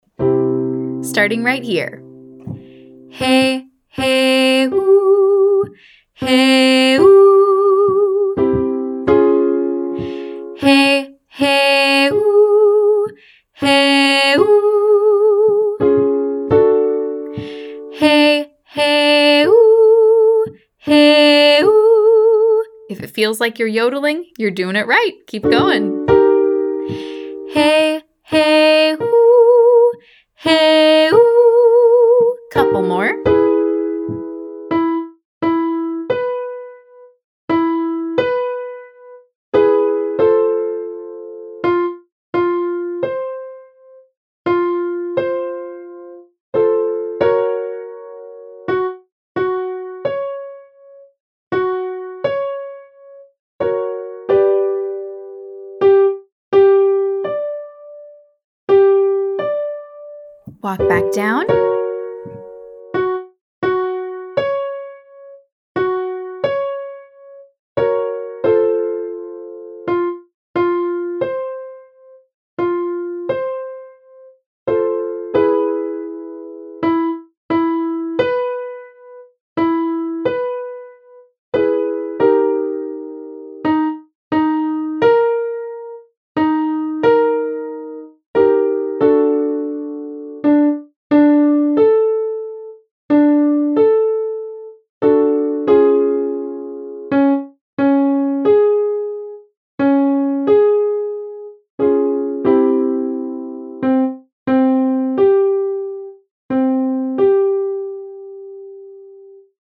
A pop flip, also called a falsetto flip or yodel, is where that abrupt register shift happens on the same syllable or vowel.
Exercise 1: HEY 1 1 Hoo 5, HEY-oo 15